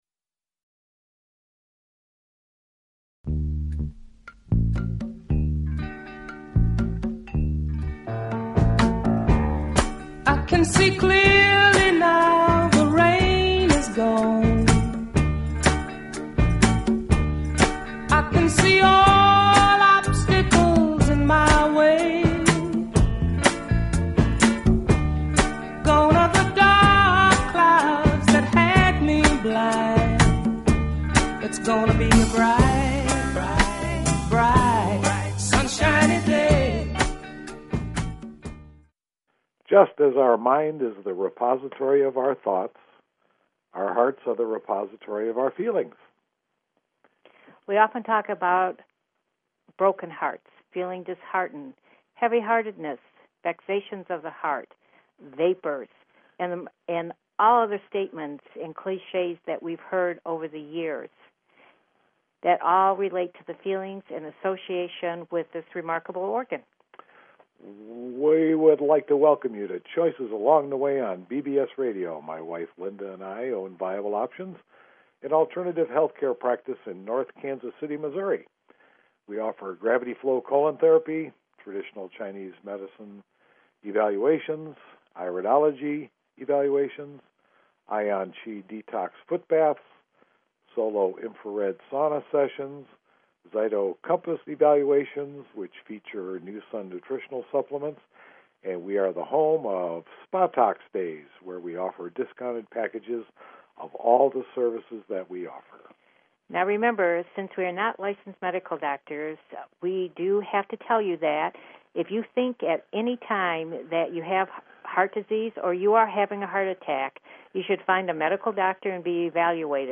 Talk Show Episode, Audio Podcast, Choices_Along_The_Way and Courtesy of BBS Radio on , show guests , about , categorized as